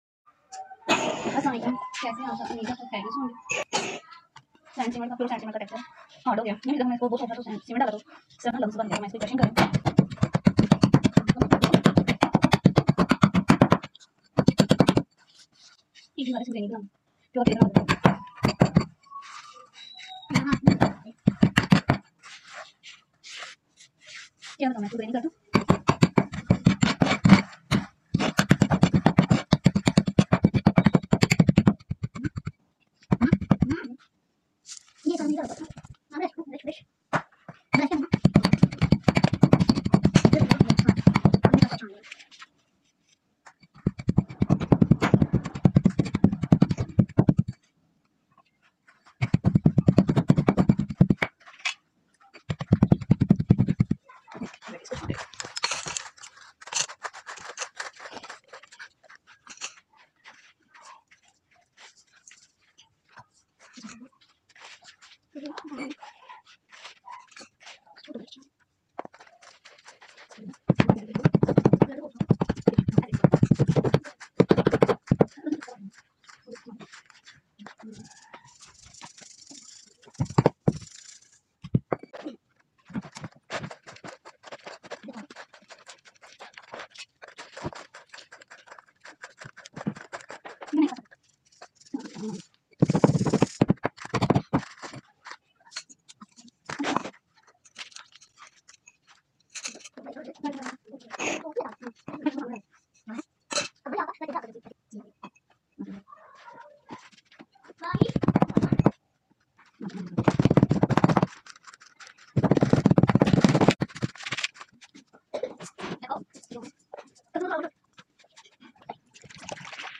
New volg crushing shifting dusty sound effects free download